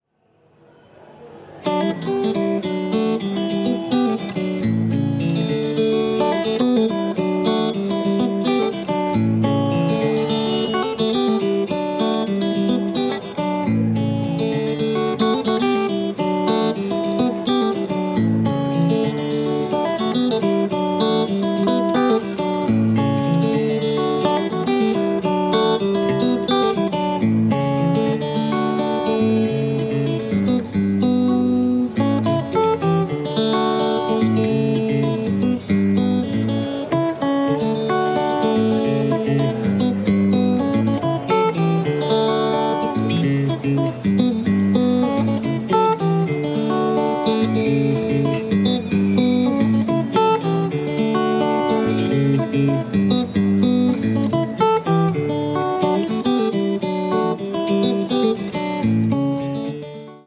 Gorgeous Congolese Guitar music